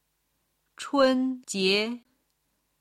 今日の振り返り！中国語発声
01-chunjie.mp3